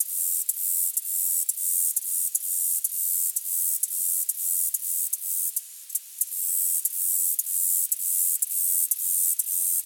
insectday_11.ogg